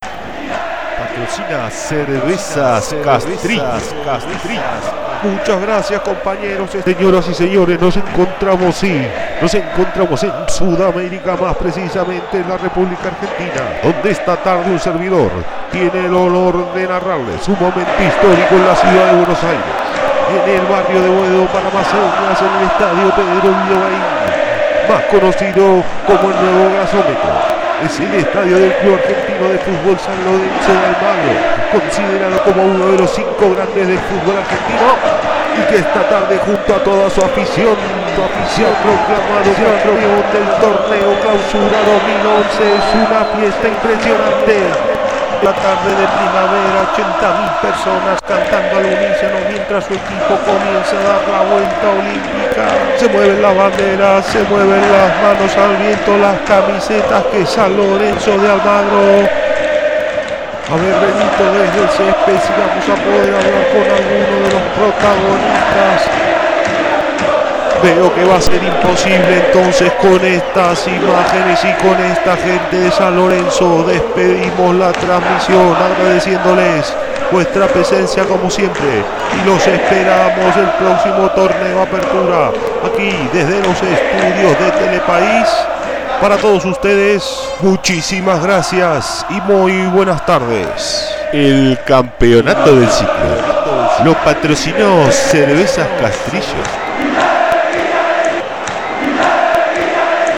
Relato deportivo
Grabación sonora de ejercicio de radiotransmisión. Simulación de la retransmisión del relato de una competición deportiva de la mano de un comentalista (locutor). Purebas de sonido.
Sonidos: Voz humana
Sonidos: Deportes